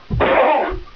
j_pain4.wav